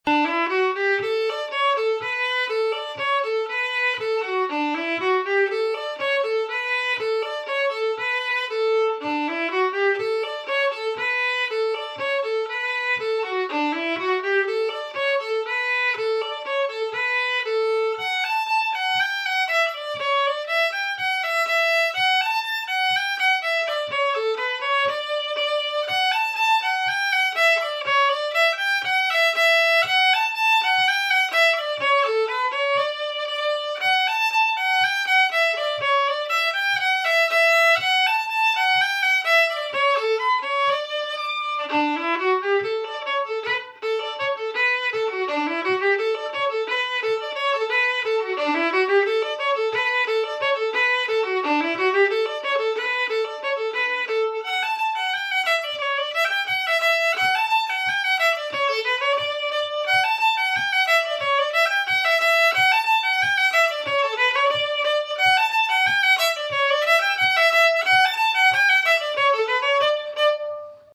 Key: D
Form: Reel
slowly for learning, then up to tempo
M:5/4
Region: Québec